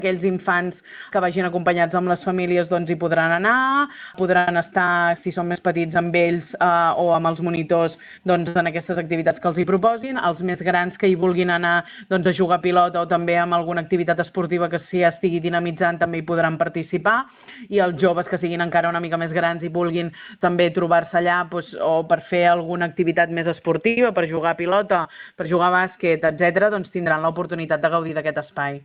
Per primera vegada, l’Ajuntament de Calella assumeix directament la gestió del projecte Pati Obert. Ho explica la regidora d’Educació i Joventut, Mariceli Santarén: